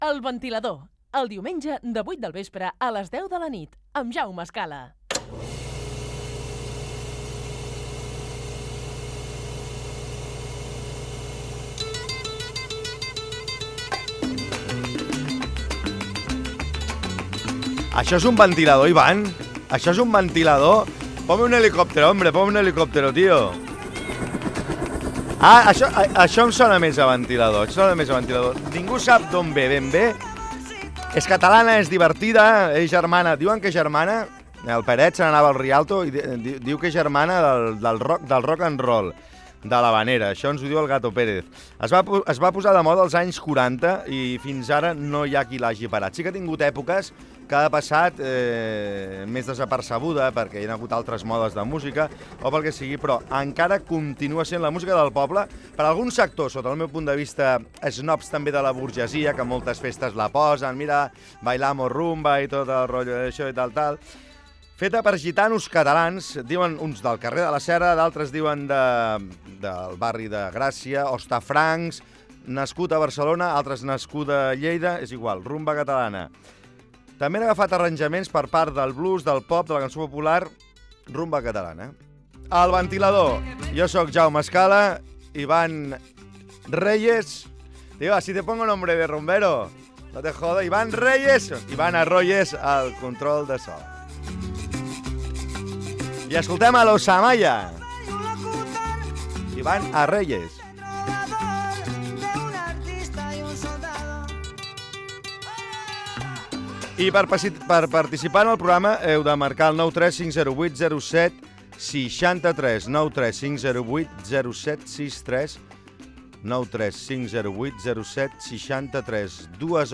Indicatiu del programa i fragment de l'inici del programa d'estiu dedicat a la rumba catalana
Musical